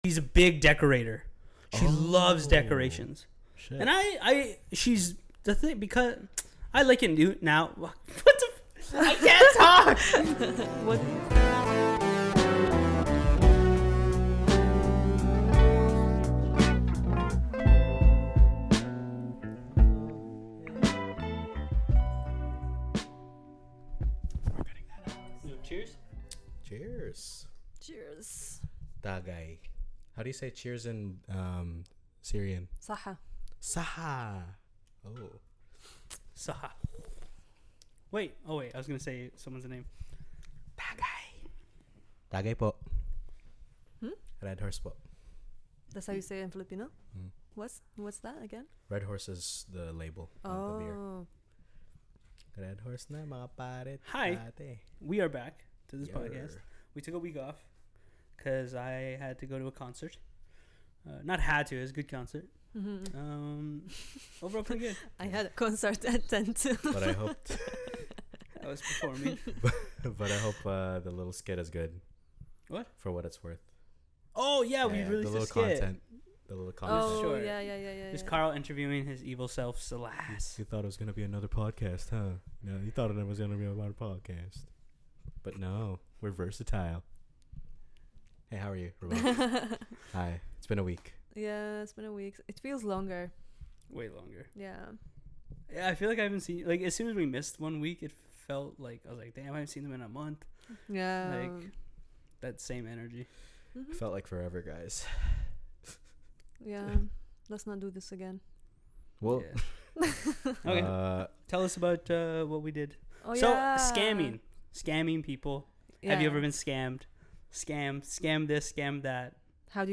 In this episode we have a rather chill and calm conversation outside our usual chaos comedy style, (maybe because we filmed late and we’re tired) either way it was a fun episode talking about how Halloween is different to our cultures, how elderly people get scammed, and a fun...